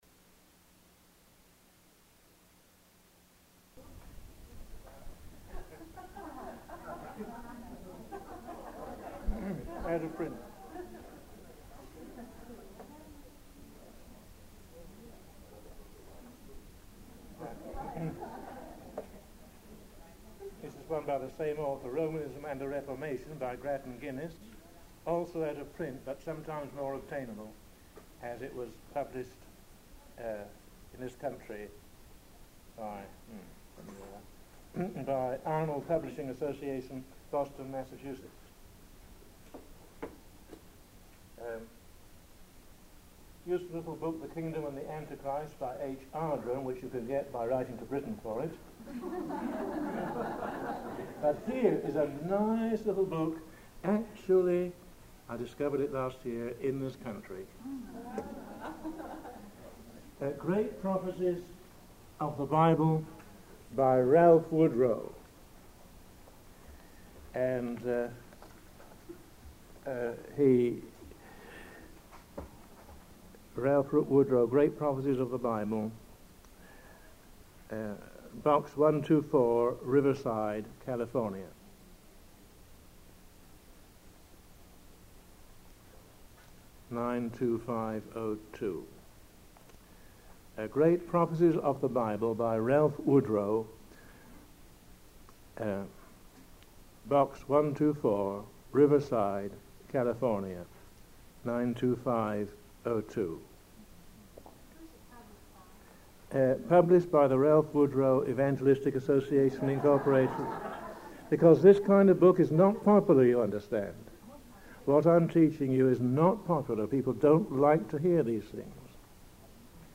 In this sermon, the speaker discusses the book 'Torch of the Testimony' by John Kennedy as a valuable history book.